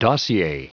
Prononciation du mot dossier en anglais (fichier audio)